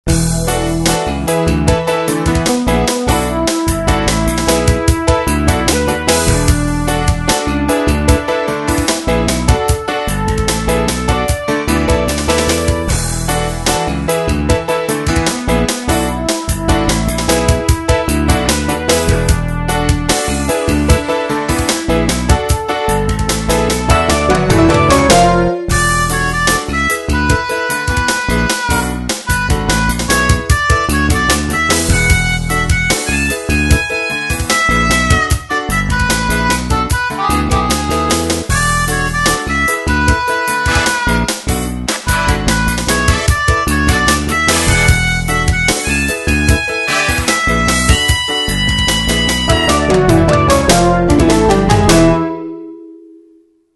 Major 明るめ ちょっと主張あり